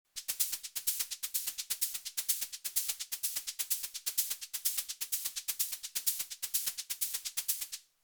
Maracas / Shaker sound on Syntakt?
I think Syntakt has the noise machine, same as rytm, it’s pretty good for shakers,
4 trigs loop, trig 1 slightly low passed, trigs 2 and 4 have panning p lock to make stereo and some other decay/high pass p locks, trig 3 has no changes
btw no filter is used, only what’s available from the noise machine